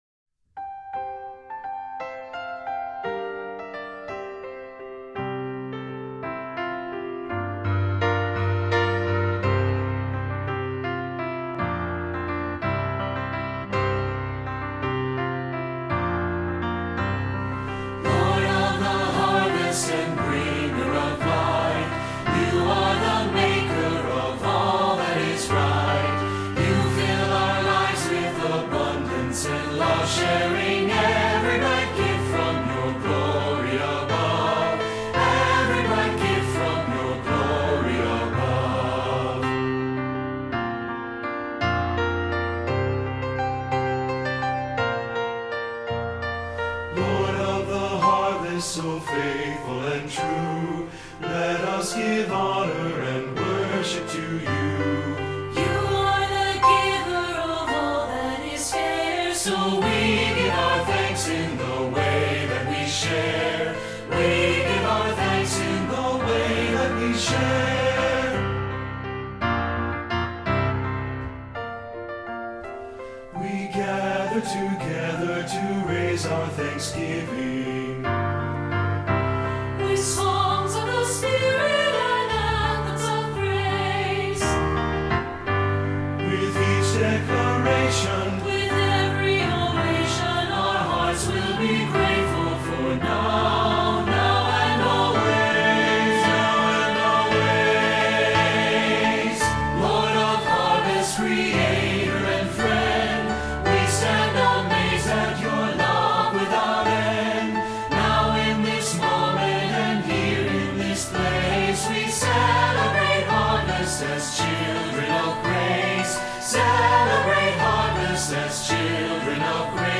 Choral Church
2 Part Mix